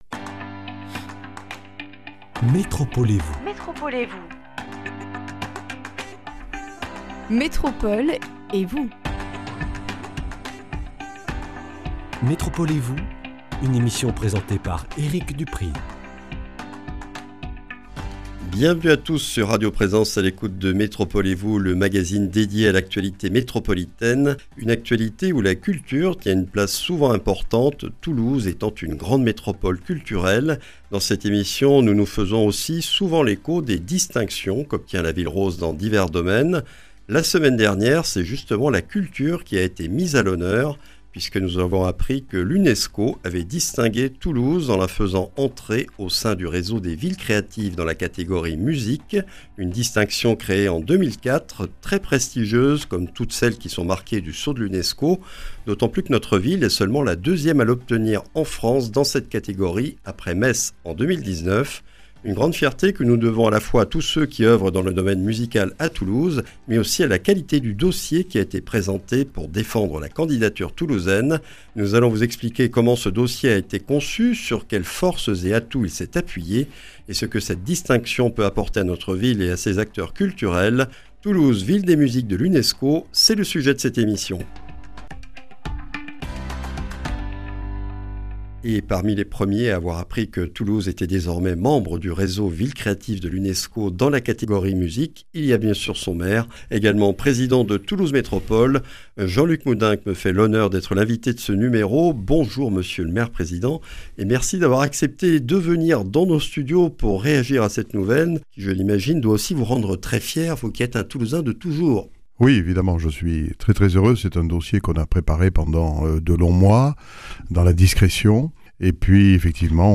Toulouse vient de faire brillamment son entrée au sein du réseau des Villes créatives de l’Unesco dans la catégorie Musique. Une distinction prestigieuse, qui récompense une longue tradition et la qualité et la vitalité de la scène musicale toulousaine dans tous les domaines, sur laquelle revient Jean-Luc Moudenc, maire de Toulouse et président de Toulouse Métropole.